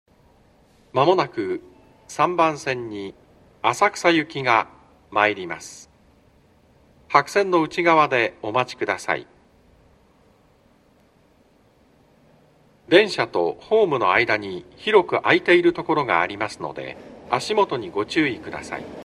B線ホームで収録すると、真上にA線の線路があるのでよくガタンゴトンと被ります・・。